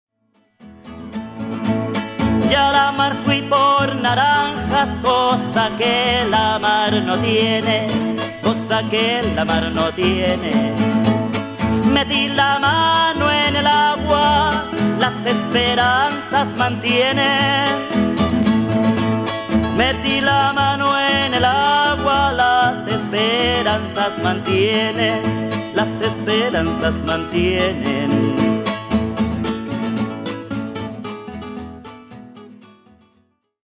; stereo.